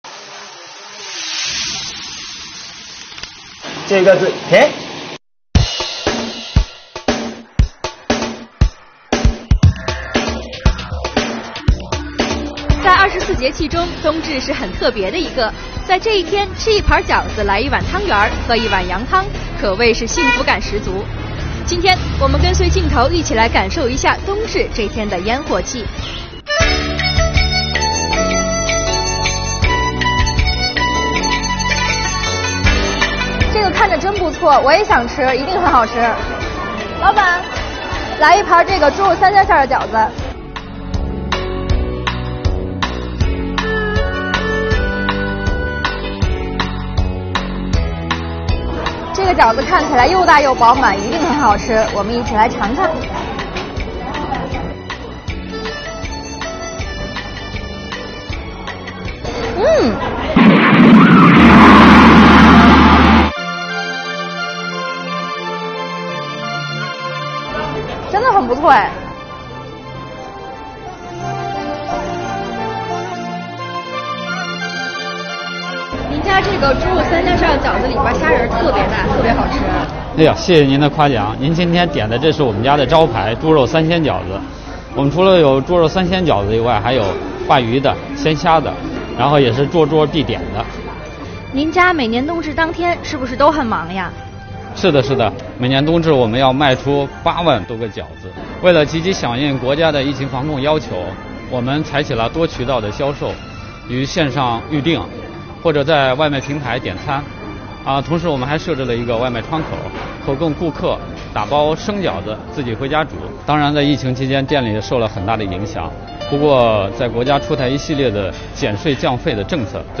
快来跟随镜头，跟本报记者一起走进北京市西城区的惠丰饺子楼，连线福建省莆田市荔城区的游记风味美食，吃饺子，品汤圆，话税收↑↑↑